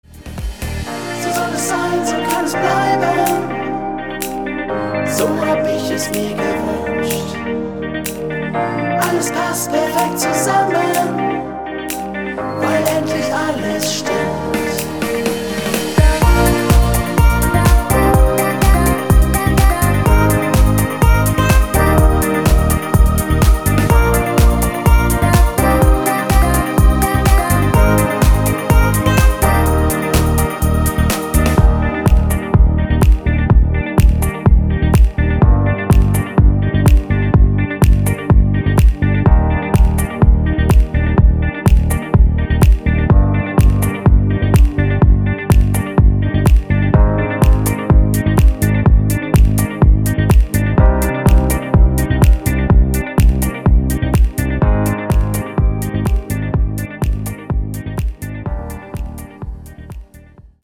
Top Mix